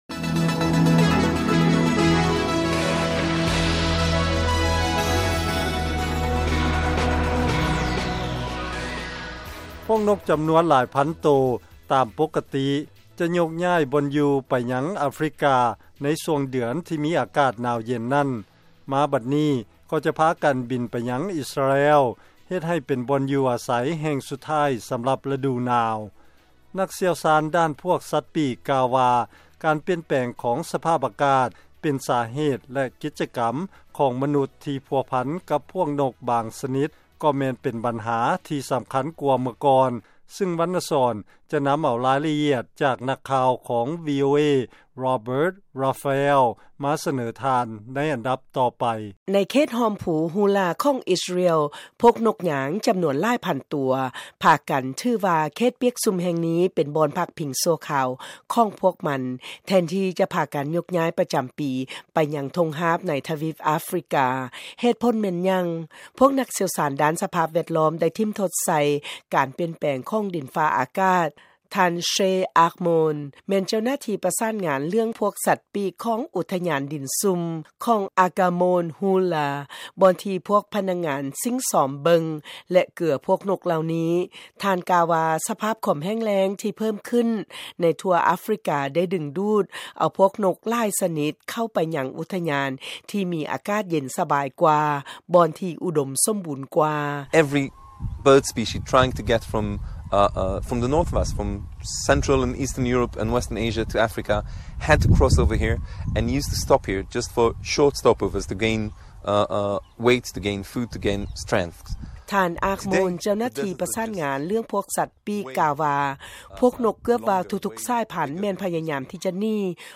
ລາຍງານການປ່ຽນແປງຂອງດິນຟ້າອາກາດ ທີ່ມີຜົນຜົນກະທົບຕໍ່ພວກນົກ